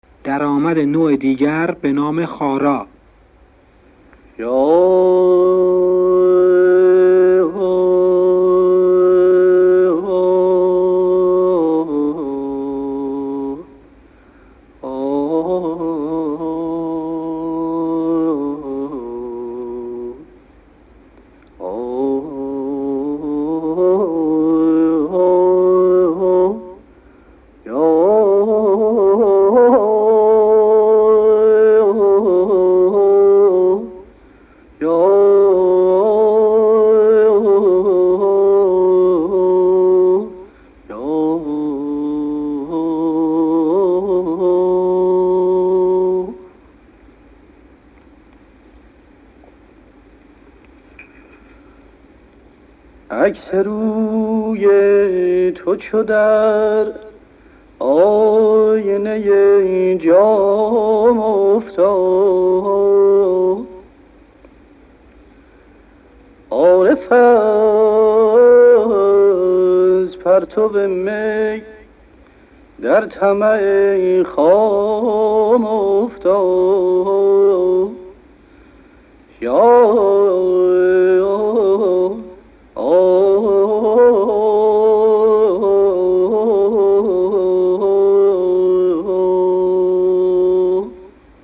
audio fileدرآمد خارا، از ردیف دستگاه شور به‌روایت محمود کریمی را می‌شنویم
– معنیِ اتخاذ شده از این گوشه‌ی آوازی چیست؟
1.karimi_daramad_e_khara.mp3